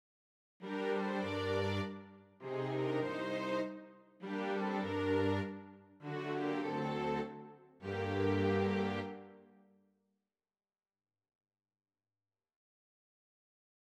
안토니오 비발디''사계'' 중 "여름" 1악장은 "나폴리 6화음에 의해 변형된 4음 하강"[11]을 통해 뜨거운 태양 아래서의 피로함과 무기력함을 강조한다.